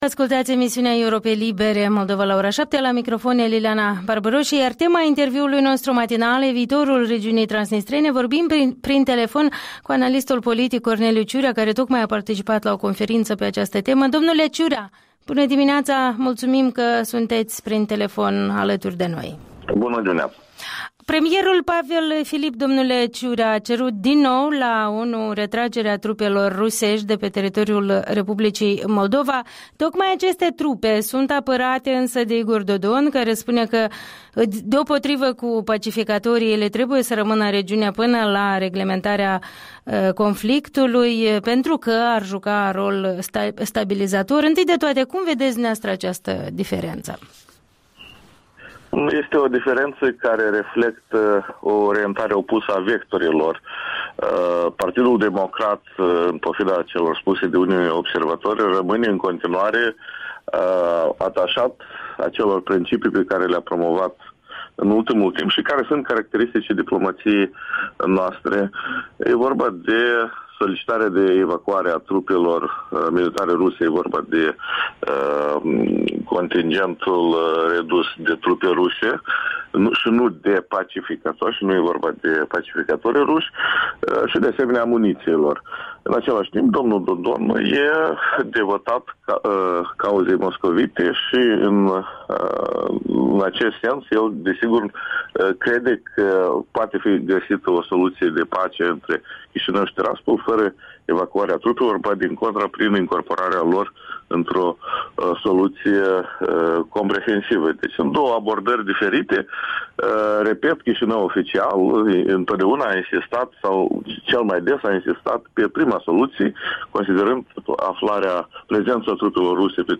Interviul dimineții despre soluții întrevăzute în chestiunea transnistreană în marginea unei conferințe internaționale.